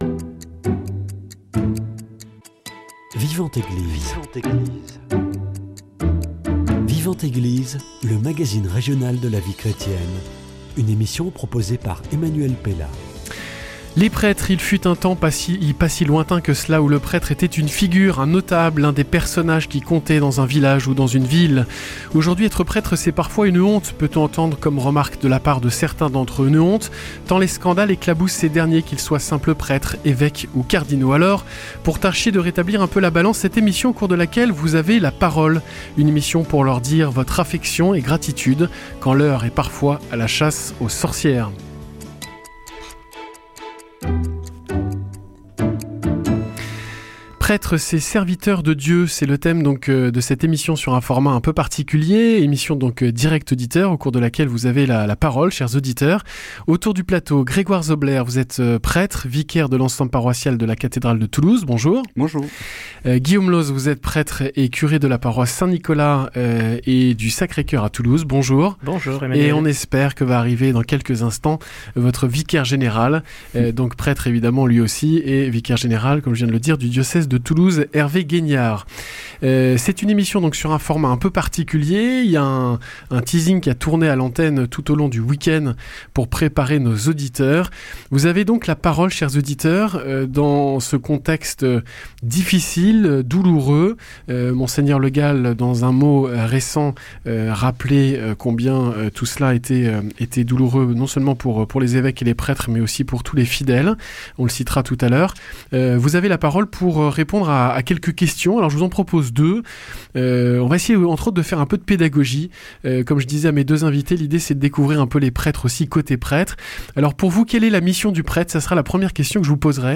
Vous nous avez donné votre témoignage. Qui sont les prêtres ?